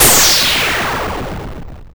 corexplode.wav